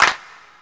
HANDCLAP.WAV